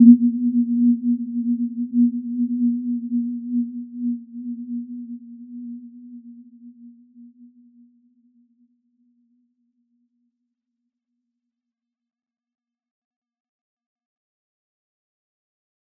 Warm-Bounce-B3-f.wav